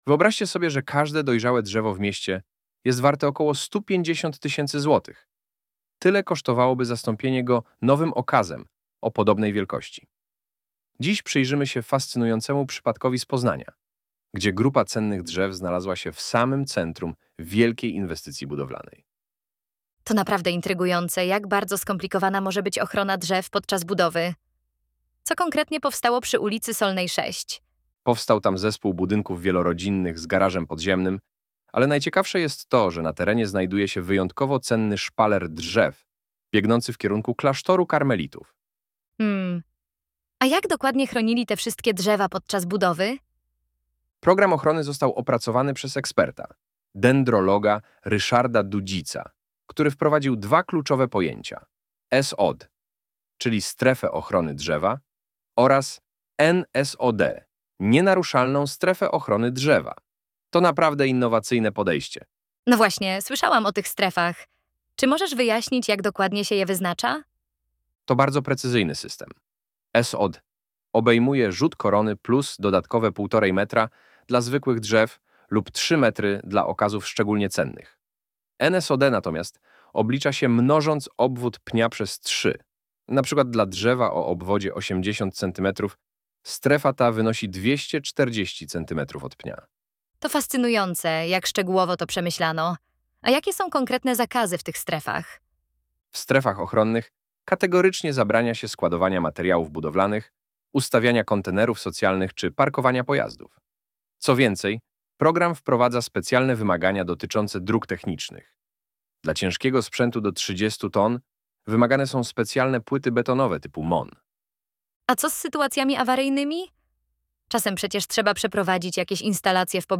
Poznajcie naszych wirtualnych inżynierów Chrisa i Jessicę , którzy opowiedzą wam wiele ciekawych historii w poniższych podcastach 😃